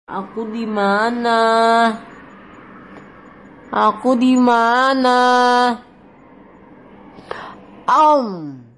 Kategori: Suara viral